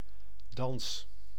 Ääntäminen
Synonyymit quatrième art raclée sixième art Ääntäminen France: IPA: [dɑ̃s] Haettu sana löytyi näillä lähdekielillä: ranska Käännös Ääninäyte Substantiivit 1. dans Suku: f .